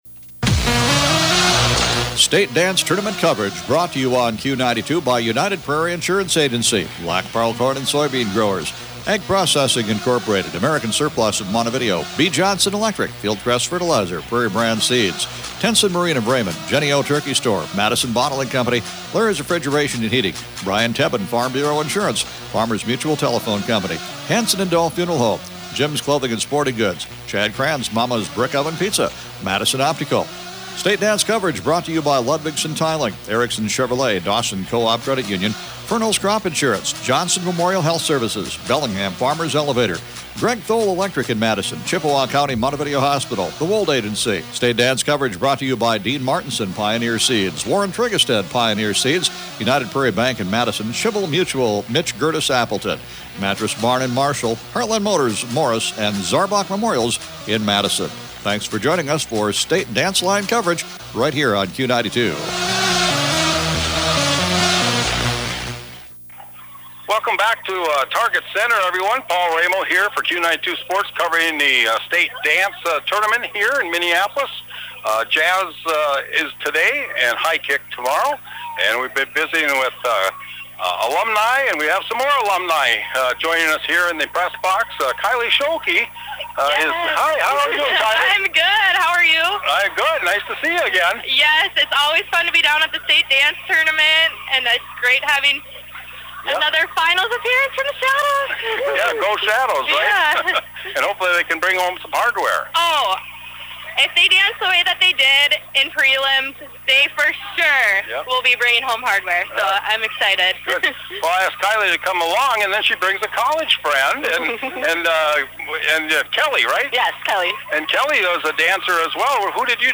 Under: Danceline, Interviews, Sports